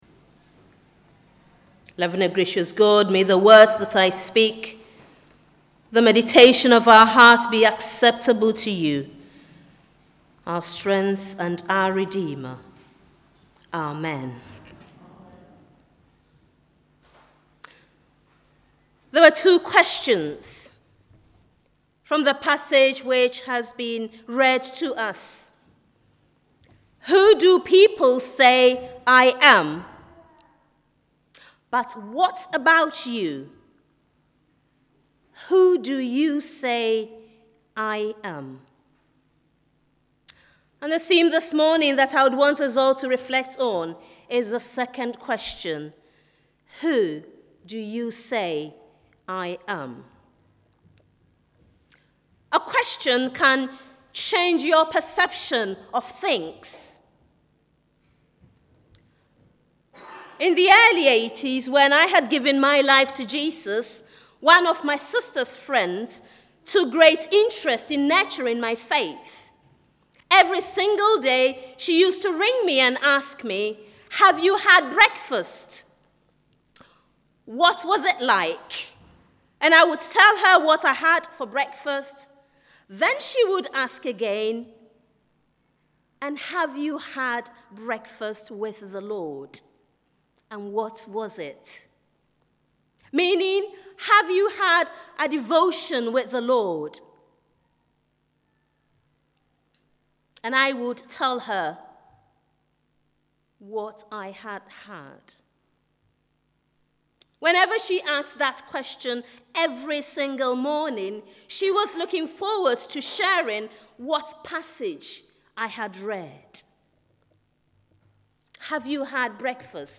Holy Communion Service